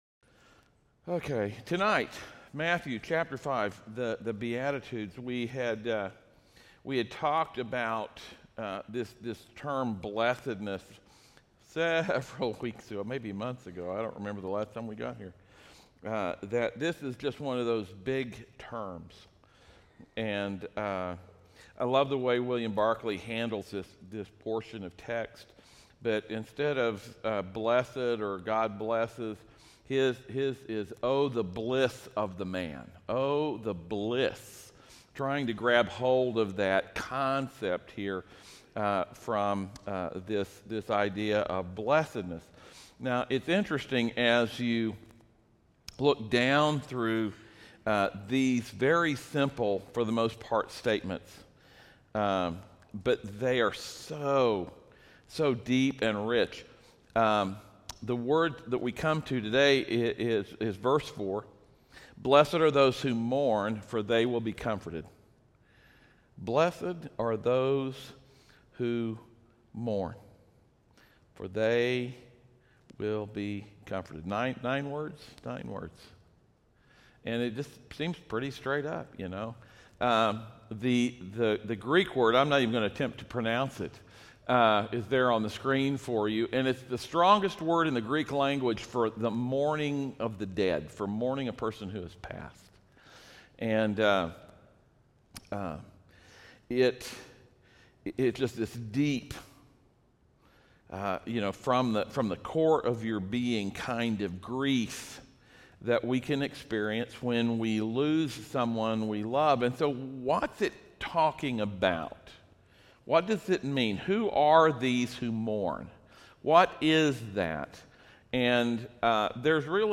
Passage: Matthew 5:4 Service Type: audio sermons